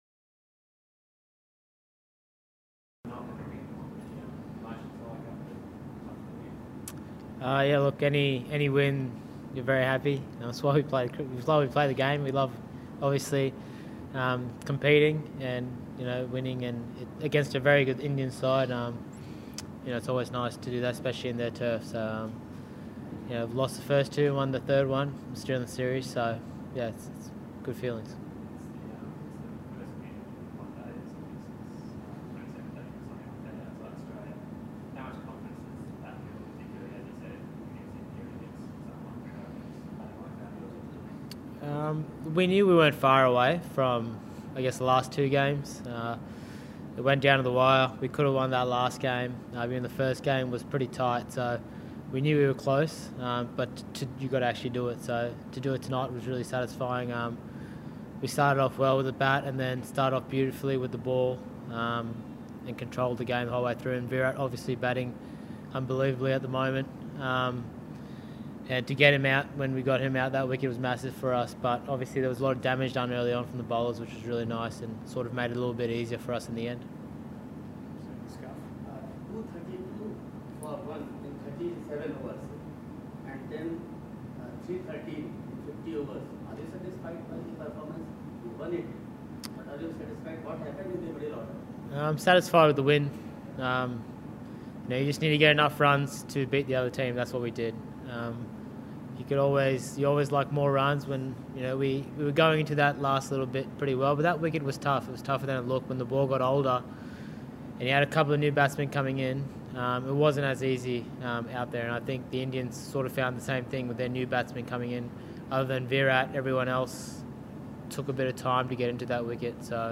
Usman Khawaja Audio|speaks to the media after the ODI against India